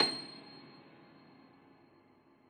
53g-pno26-C6.wav